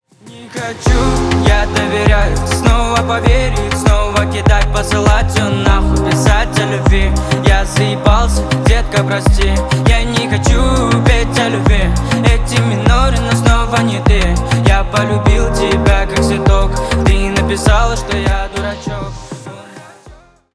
Поп Музыка
грустные # спокойные